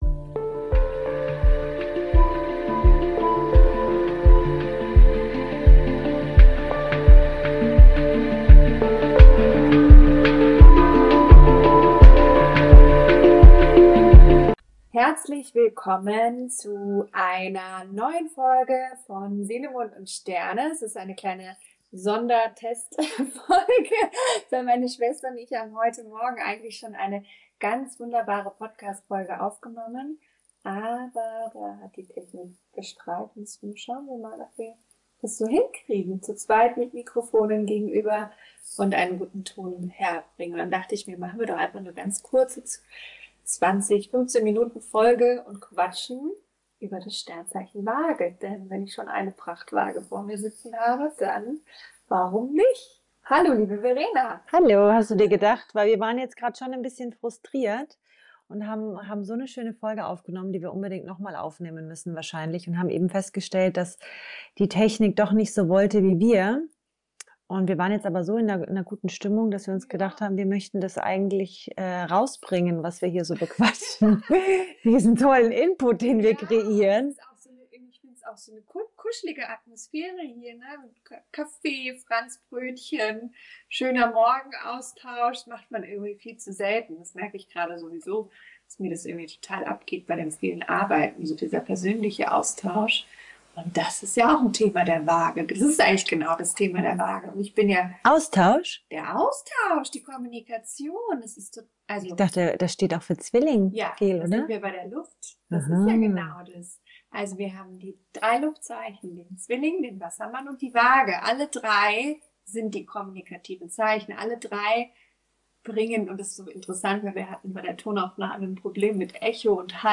Ein lockerer Sister-Talk bei Kaffee & Franzbrötchen – ehrlich, leicht und mit einem Augenzwinkern.